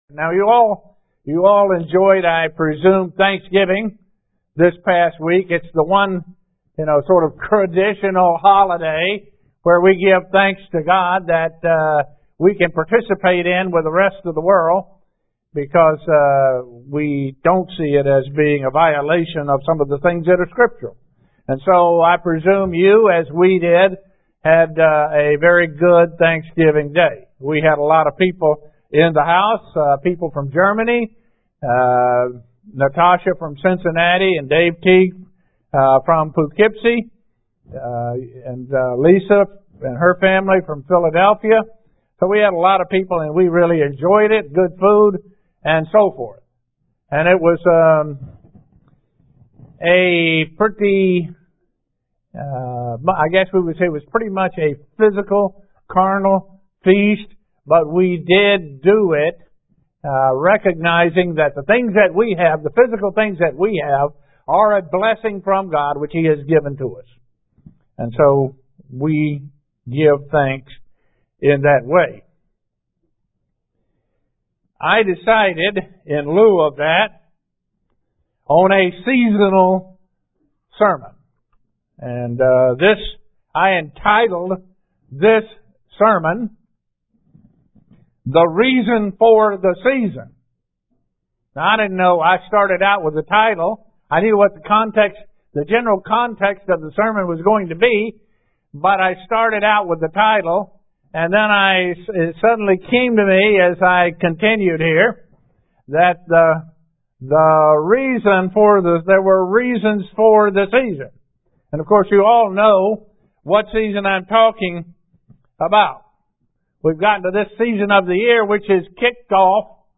Given in Buffalo, NY
Print What is the reason for the holiday season and what the Bible teaches about the Holy Days UCG Sermon Studying the bible?